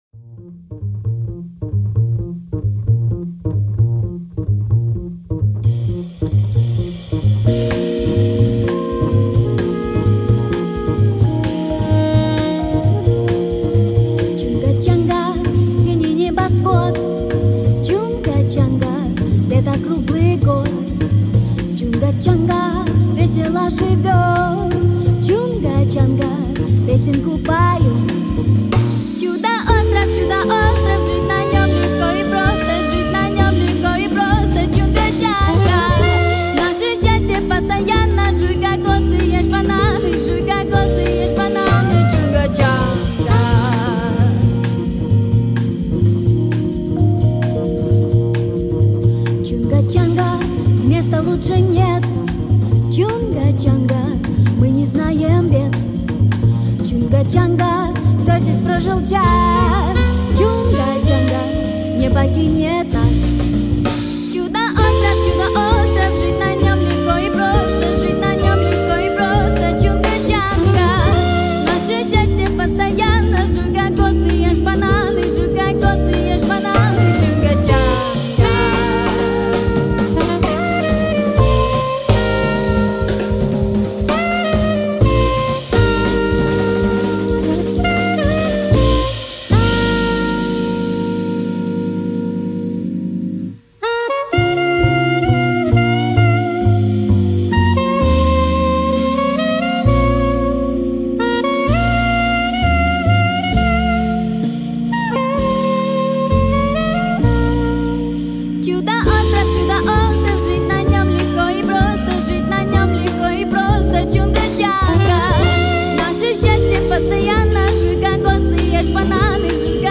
джазовая версия